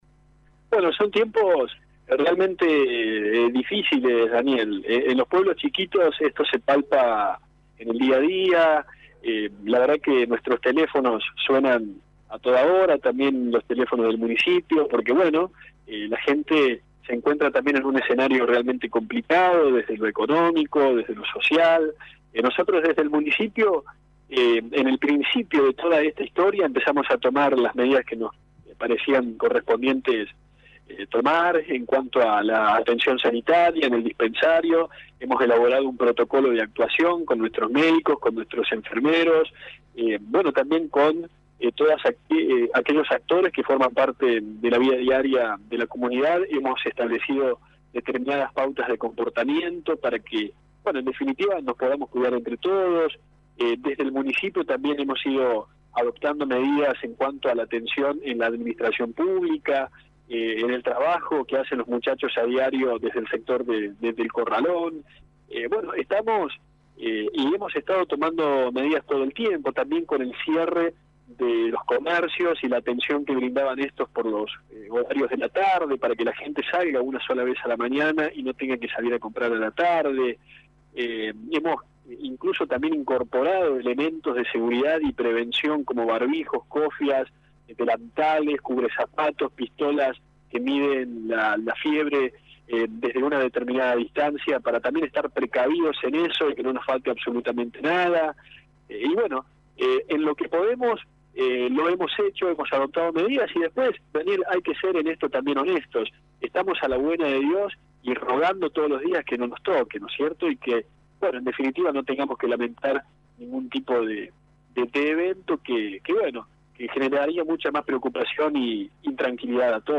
El jefe comunal habló con Radio Show y explicó cual es el objetivo de esta iniciativa, que ya habría sido imitada por otros intendentes de la zona.